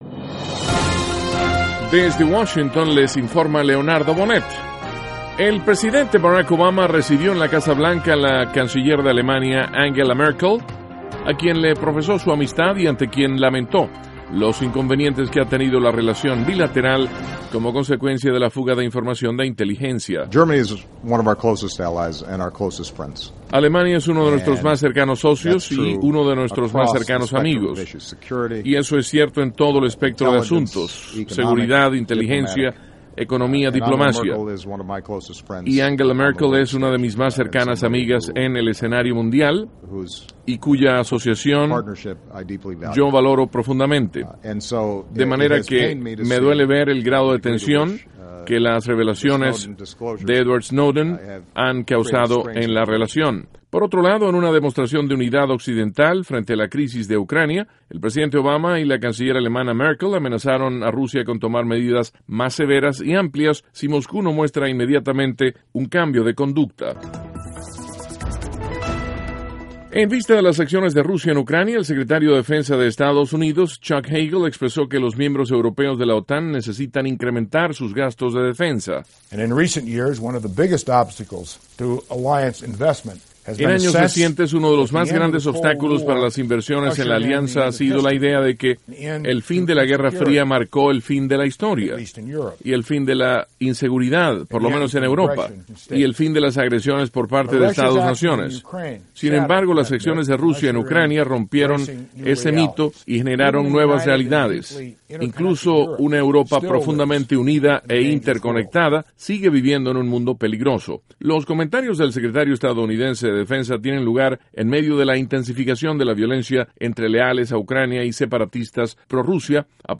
Duración: 2:55   Contenido:   1.- El presidente Obama recibe en la Casa Blanca a la canciller alemana Angela Merkel. (Sonido Obama)   2.- El secretario de Defensa de Estados Unidos, Chuck Hagel, insta a miembros de la OTAN a incrementar gastos de defensa. (Sonido Hagel)   3.-Senador Marco Rubio recibe con agrado que Comisión de Relaciones Exteriores del Senado analizará crisis política de Venezuela, el jueves, 8 de mayo.